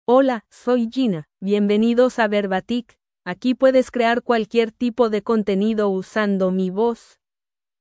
Gina — Female Spanish (United States) AI Voice | TTS, Voice Cloning & Video | Verbatik AI
Gina is a female AI voice for Spanish (United States).
Voice sample
Listen to Gina's female Spanish voice.
Female
Gina delivers clear pronunciation with authentic United States Spanish intonation, making your content sound professionally produced.